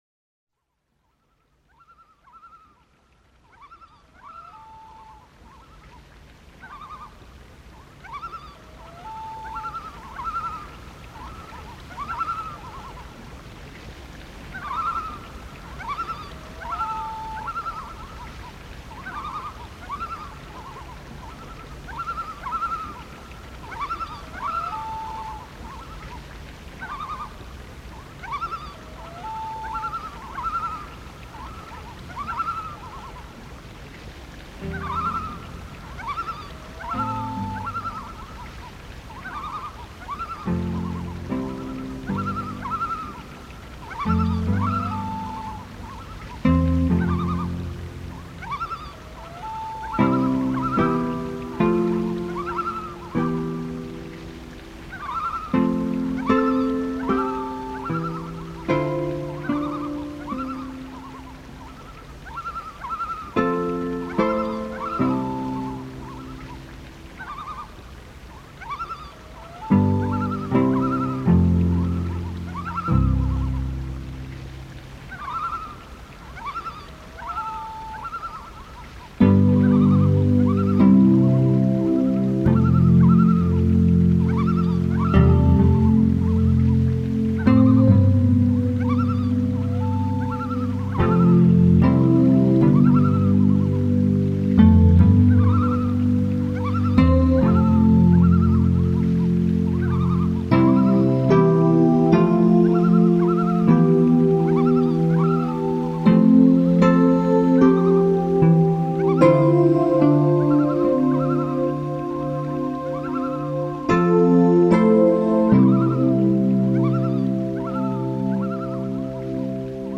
Index of //MEDIA SET/LASTNEWS/MUSIC/звуки природи/Крики морских птиц/
звуки природи